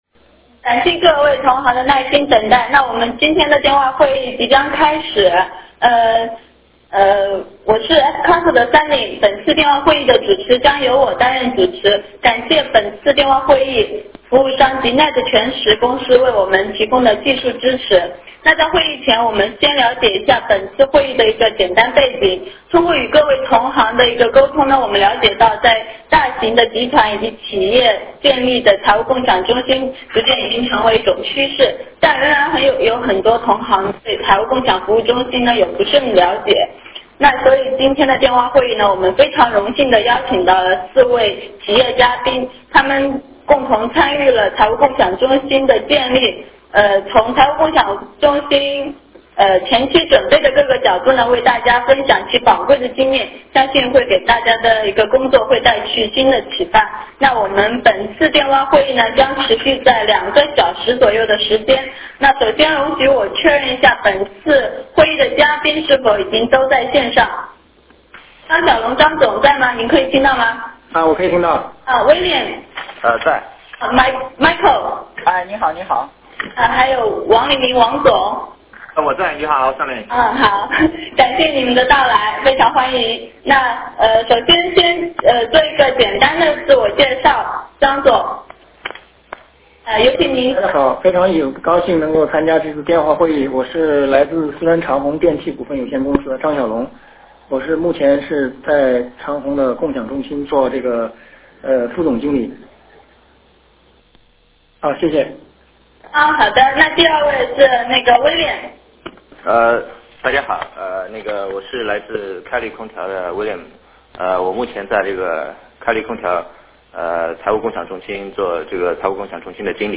电话会议
Q&A互动环节